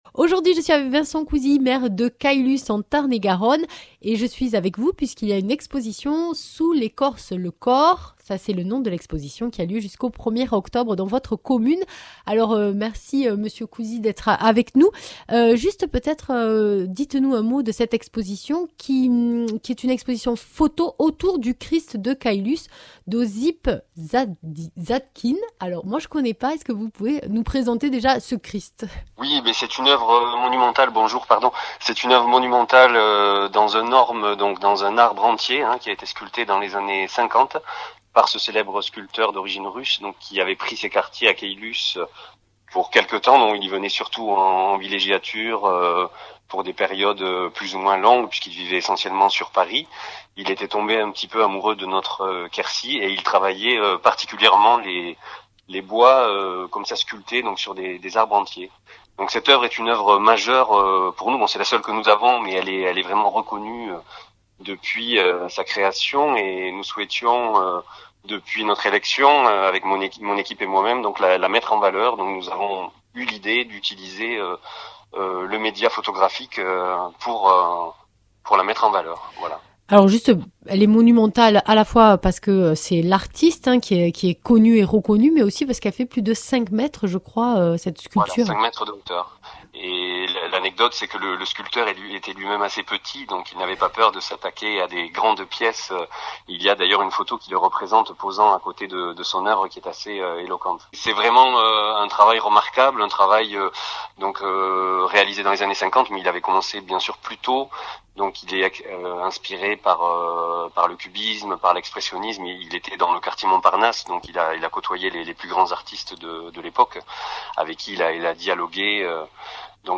Interviews
Invité(s) : Vincent Cousi, maire de Caylus (Tarn et Garonne)